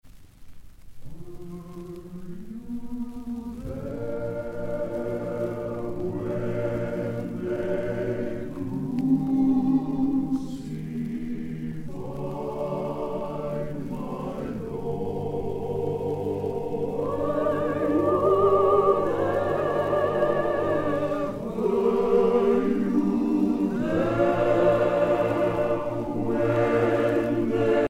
Spirituals
Pièce musicale éditée